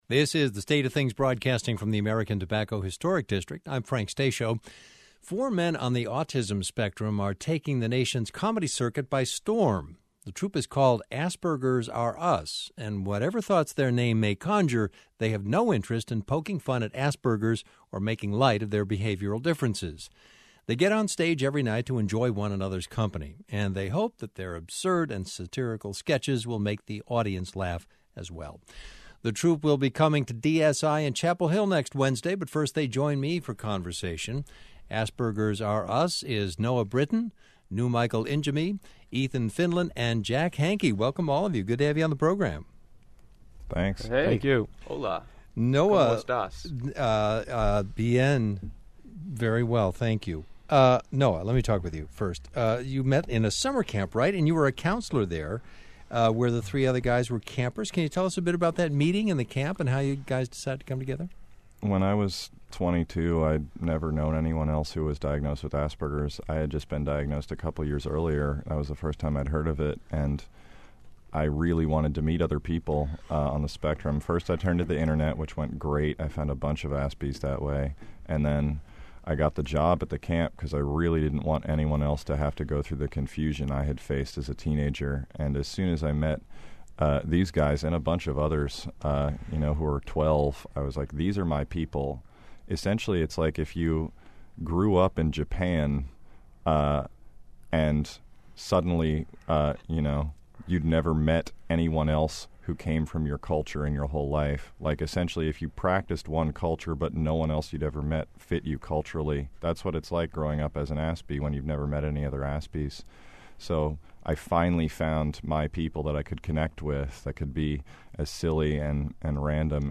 A conversation with comedians